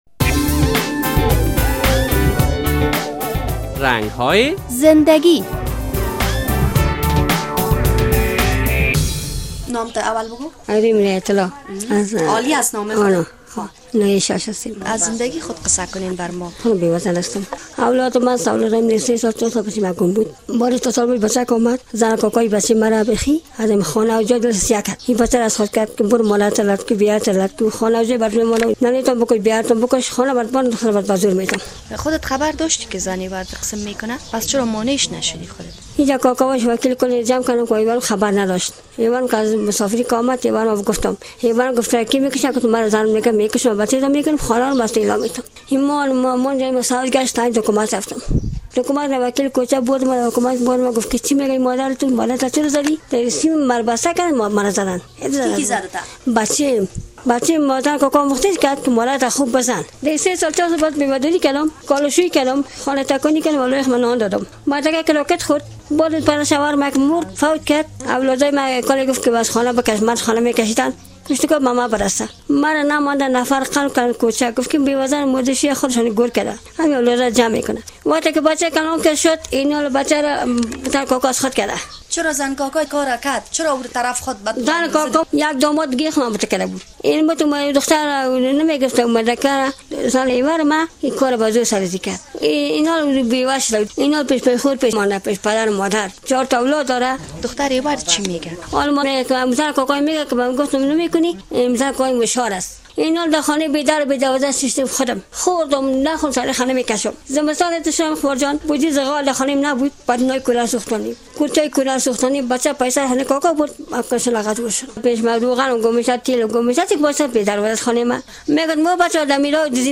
در این برنامهء رنگ های زنده گی با یک زن بیوه صحبت شده است. این زن از زنده گی و مشکلاتی که در زنده گی اش دارد قصه کرده است.